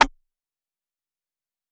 Snare Metro.wav